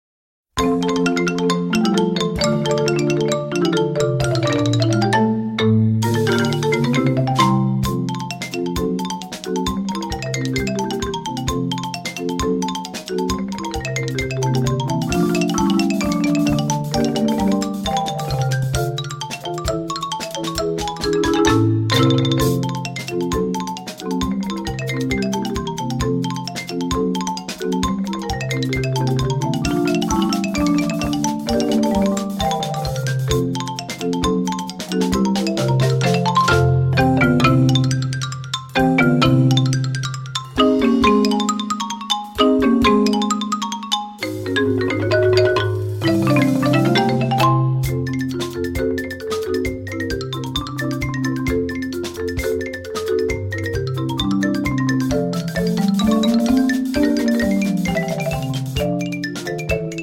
chamber percussion group